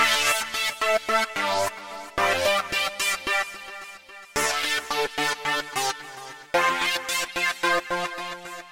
描述：Ambient Loops
标签： 110 bpm Ambient Loops Synth Loops 2.48 MB wav Key : Unknown
声道立体声